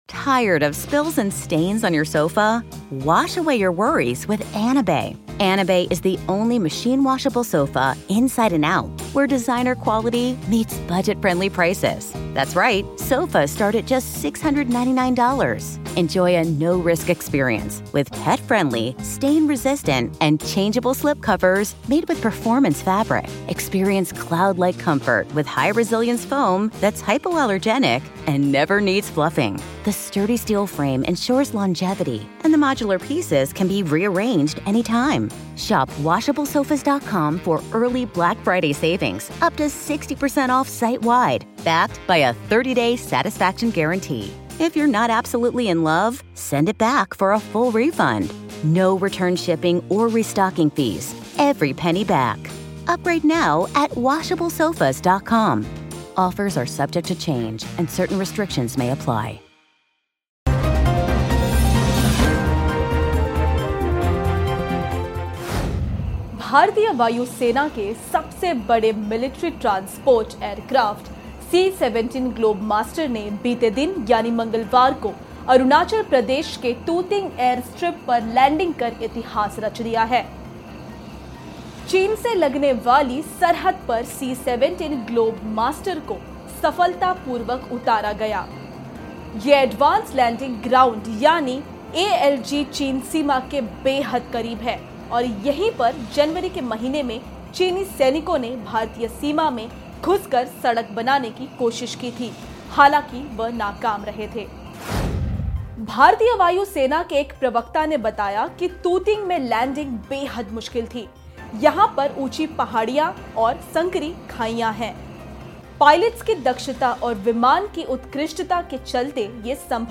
News Report / वायुसेना ने रचा इतिहास,चीन के करीब हुई C17 ग्लोबमास्टर की सफल लैंडिंग